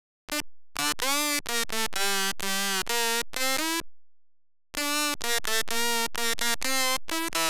BASS HOUSE KITS
Ace_126 – Bass_Lead